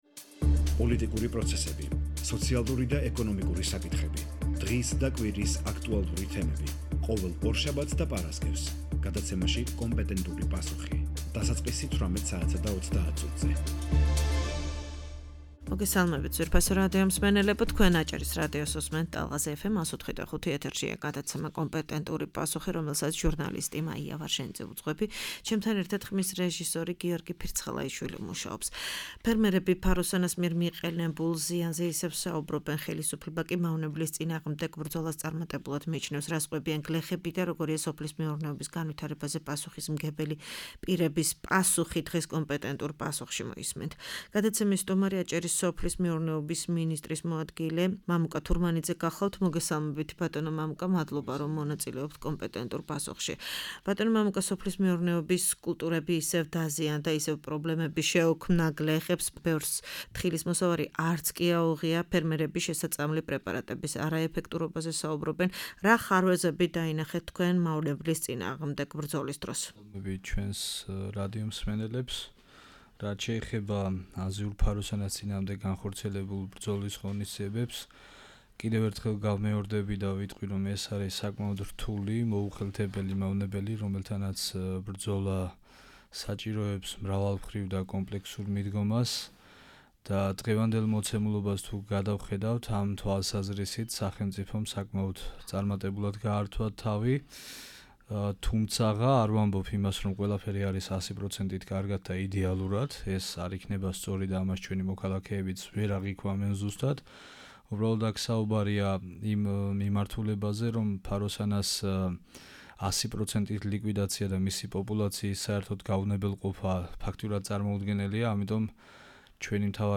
ფერმერები ფაროსანას მიერ მიყენებულ ზიანზე ისევ საუბრობენ-ხელისუფლება კი მავნებლის წინააღმდეგ ბრძოლას წარმატებულად მიიჩნევს-ამ თემაზე „კომპეტენტურ პასუხში“ აჭარის სოფლის მეურნეობის მინისტრის მოადგილე მამუკა თურმანიძე საუბრობს.